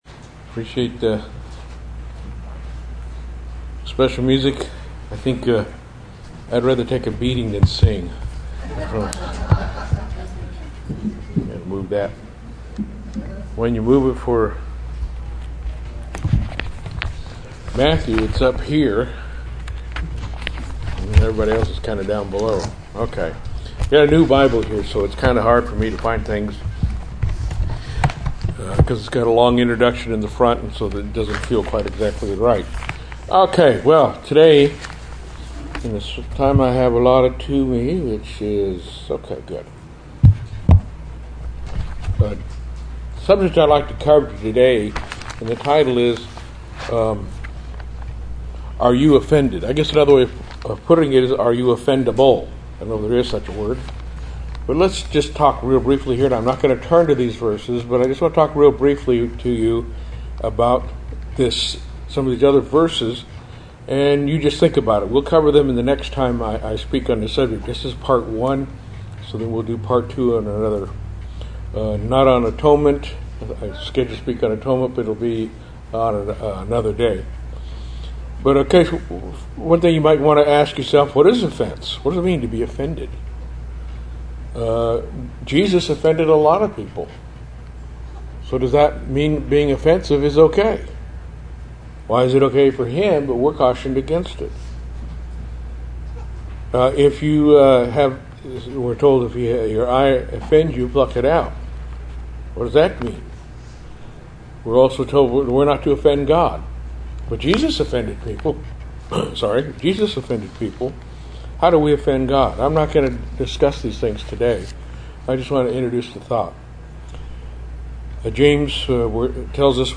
Sermons
Given in Fort Worth, TX